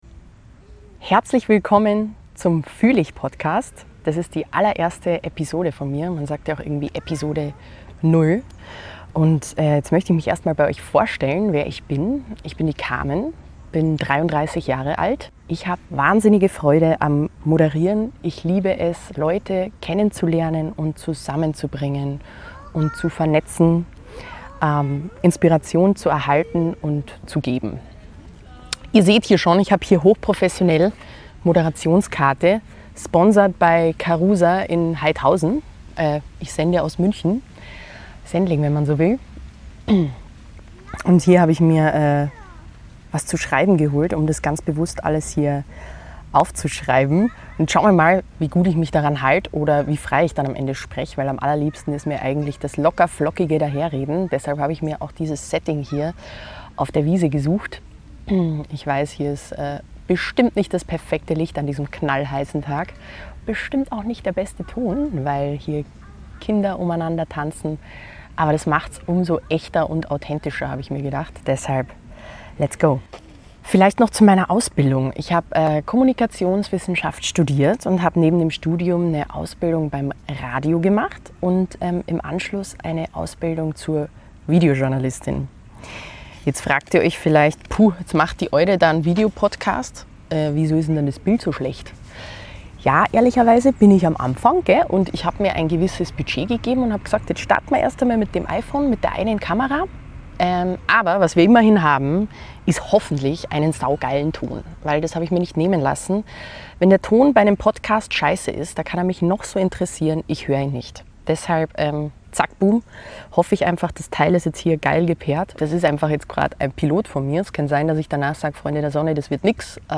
Ich erzähle, warum ich mich entschlossen habe, einfach zu starten – mit iPhone, Wiesen-Setting und einer großen Portion Offenheit.